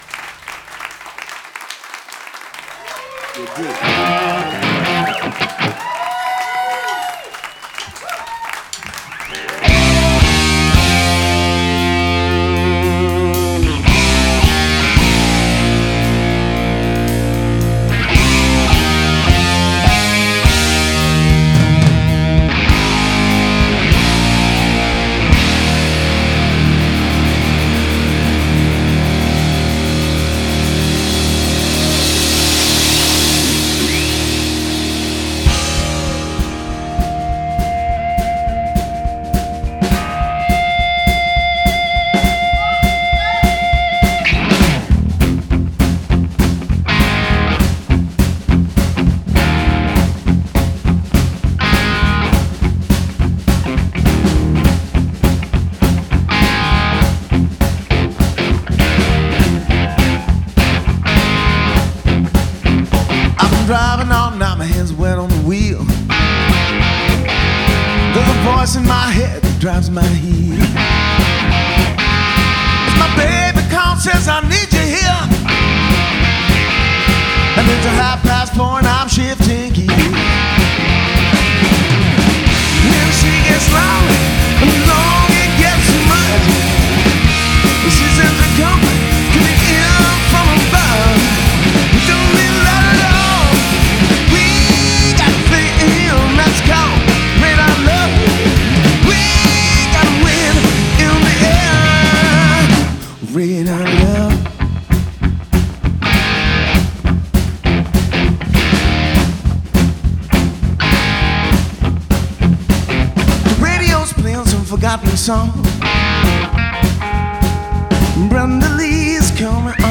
straight ahead classic 70’s Rock